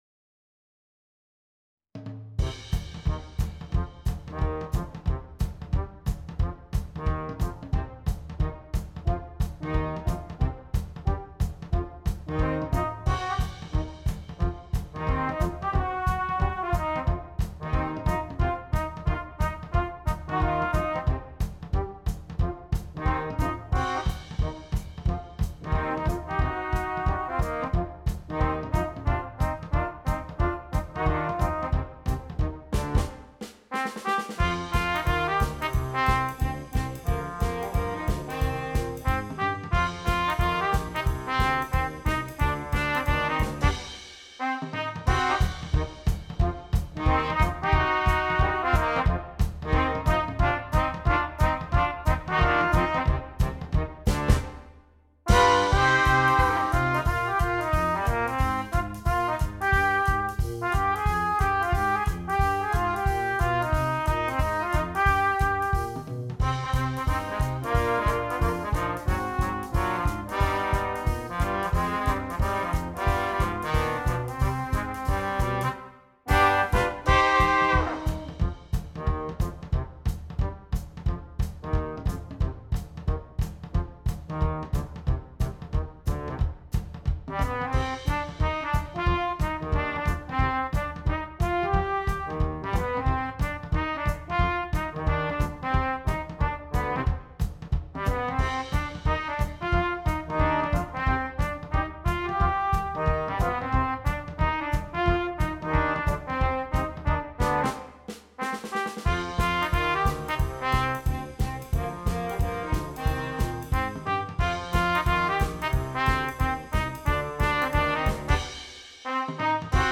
Gattung: Für Blechbläserquintett
Besetzung: Ensemblemusik für 5 Blechbläser